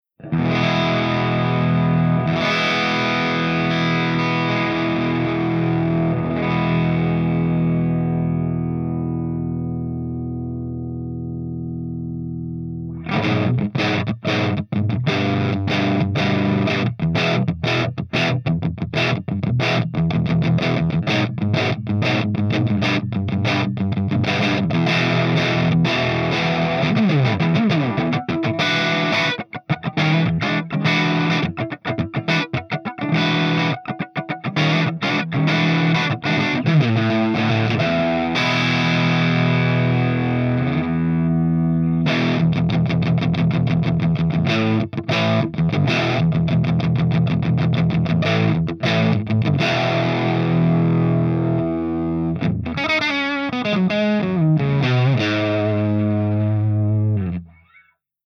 108_PLEXI_CH2EXTRADRIVE_GB_SC.mp3